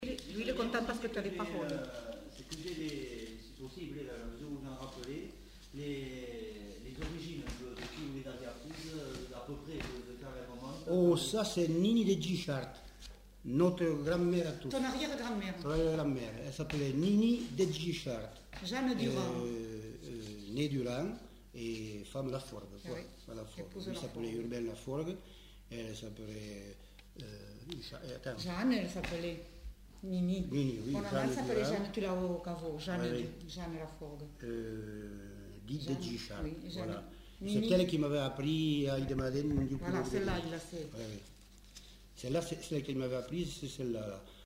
Lieu : Bagnères-de-Luchon
Genre : témoignage thématique